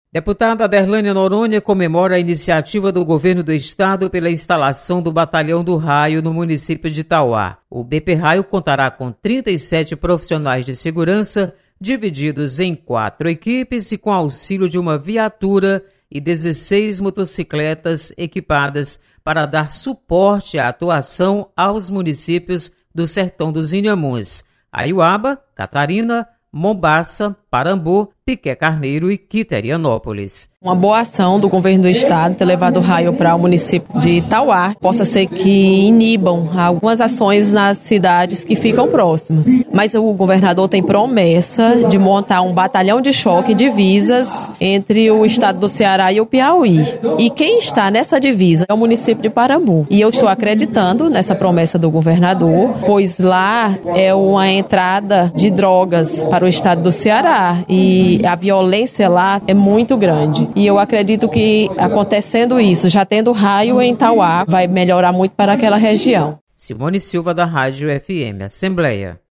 Deputada Aderlânia Noronha elogia instalação de batalhão do Raio em Tauá. Repórter